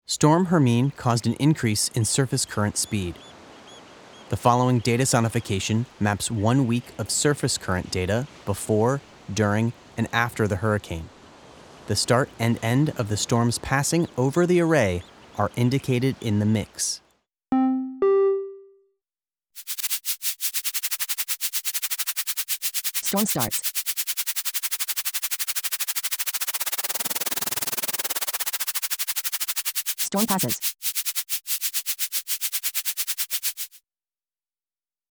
Storm-Hermine-Audio-Display-8-Currents-Sonification.mp3